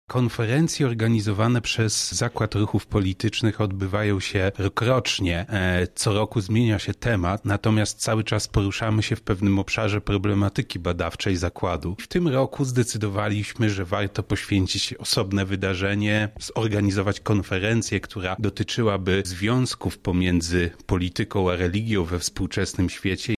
Wczoraj, na wydziale Politologii UMCS, odbyła się konferencja pod nazwą „Politologia religii”.